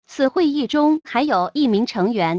conf-one_other_member_conference.wav